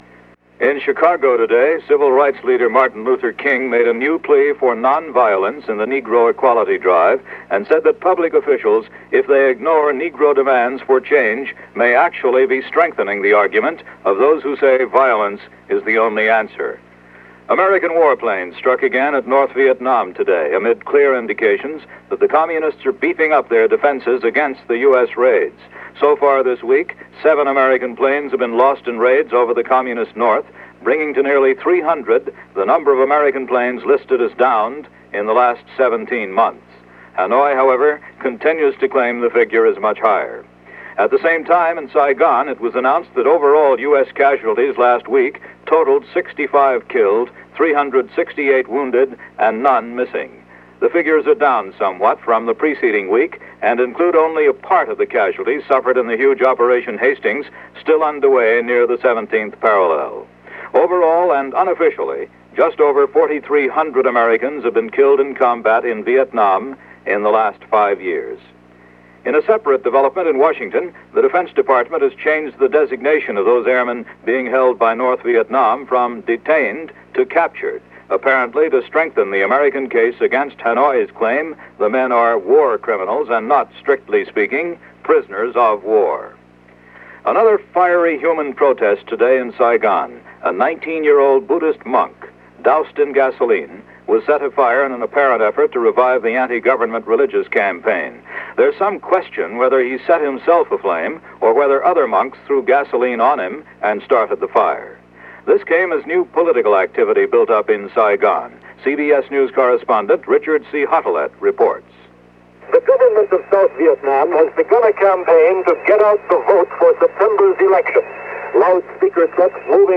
All that, and a lot more for this estranged and confused July 25th, 1966 as presented by CBS Radio’s The World Tonight.